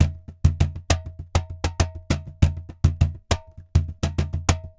Cajon_Baion 100_2.wav